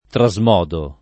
trasmodo [ tra @ m 0 do ]